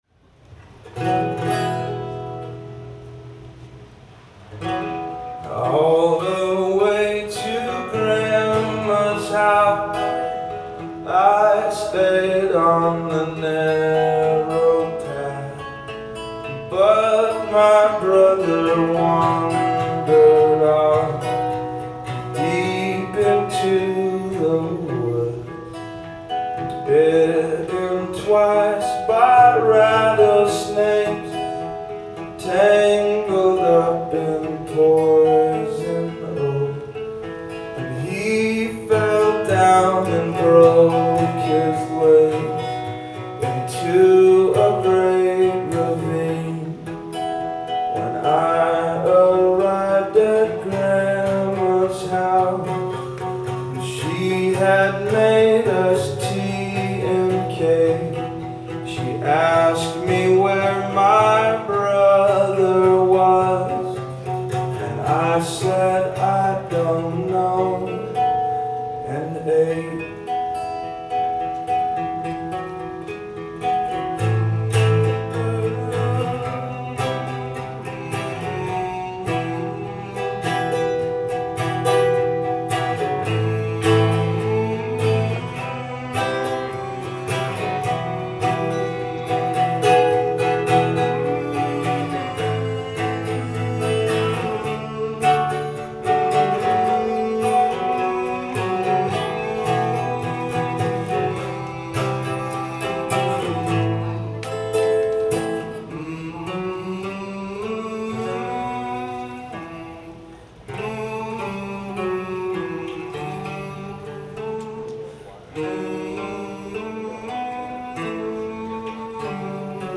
Live at TT the Bears